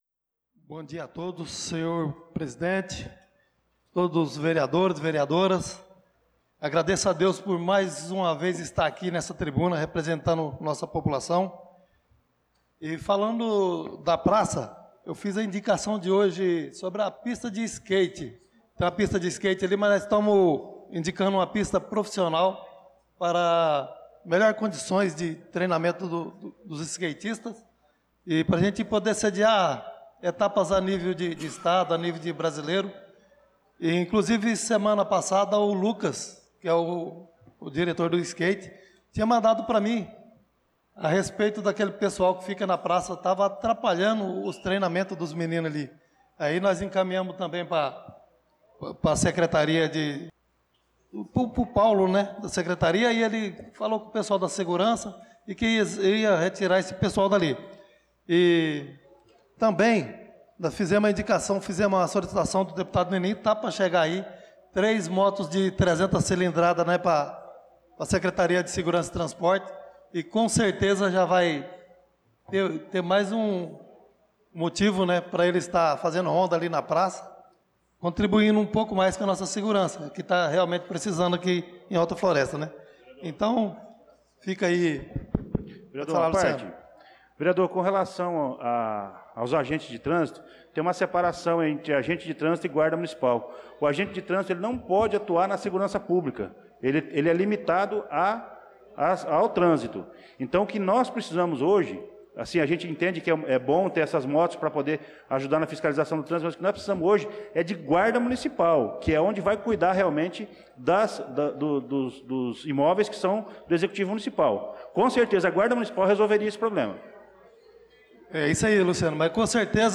Pronunciamento do vereador Chicão Motocross na Sessão Ordinária do dia 02/06/2025